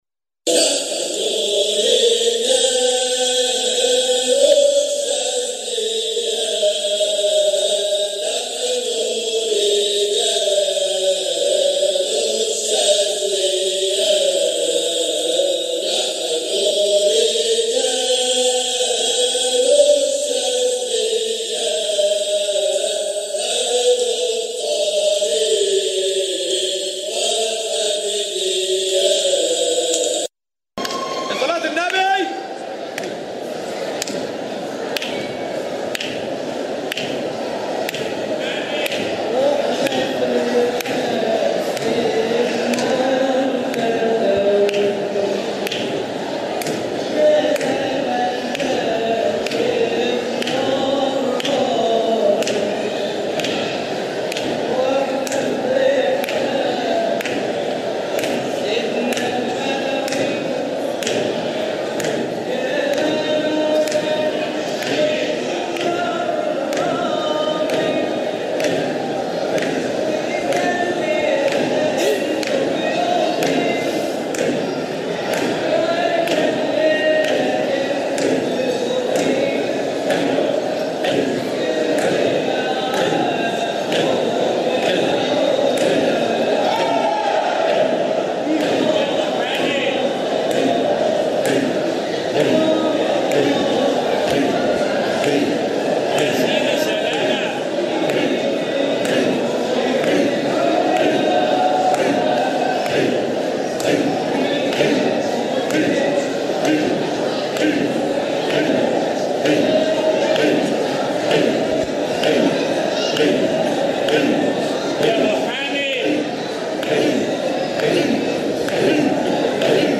مقاطع من احتفالات ابناء الطريقة الحامدية الشاذلية بمناسباتهم
جزء من حلقة ذكر بمسجد سيدنا احمد البدوى قُدس سره 2017